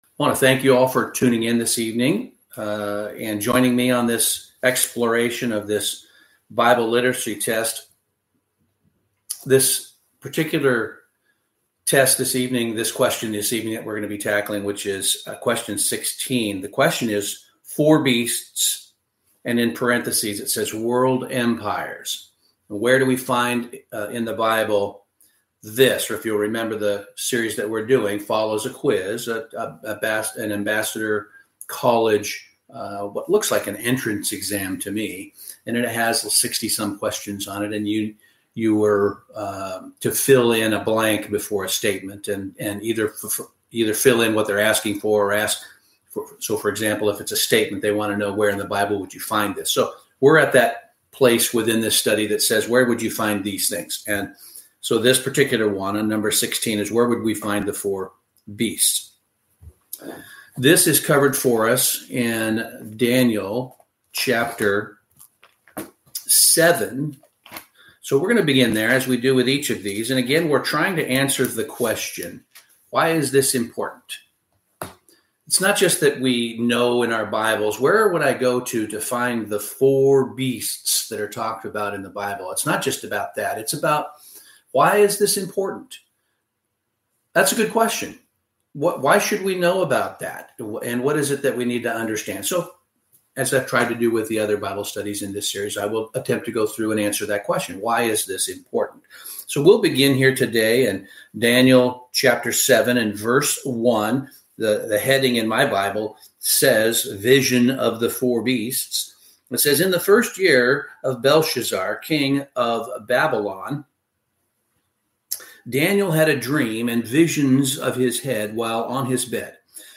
Sermons
Given in Tacoma, WA Olympia, WA